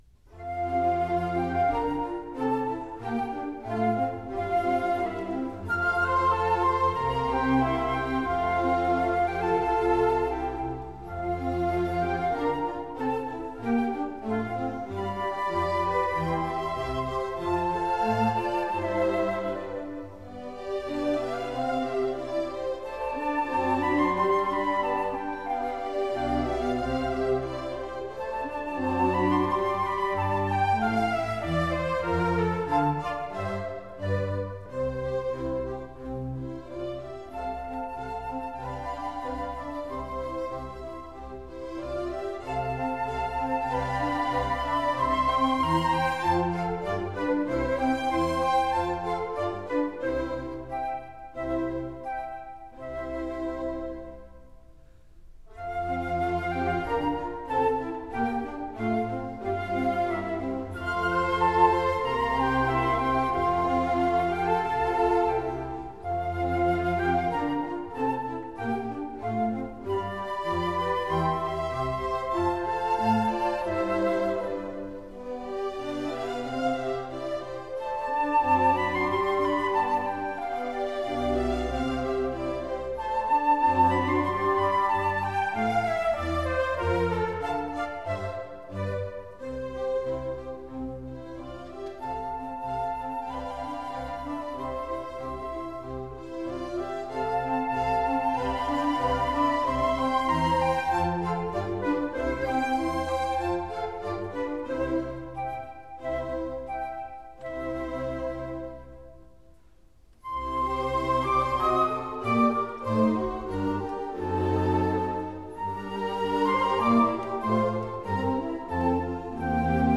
» 1 - Symphonies